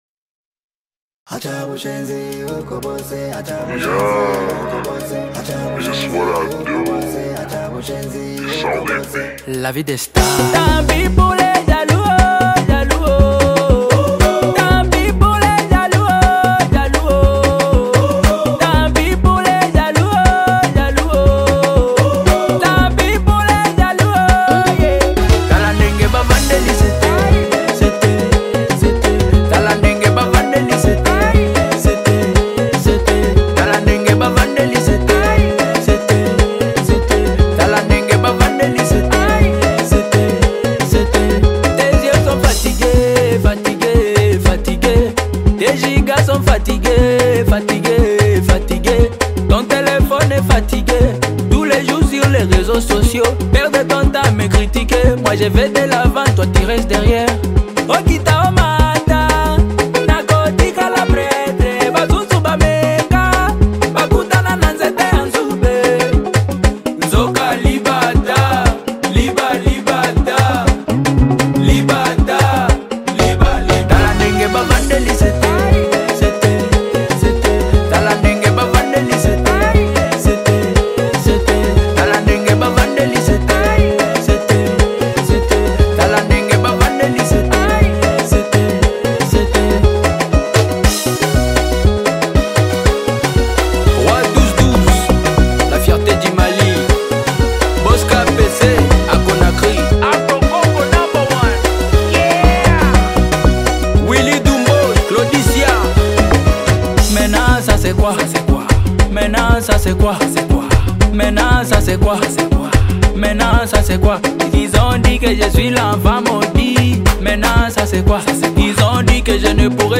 Congo Music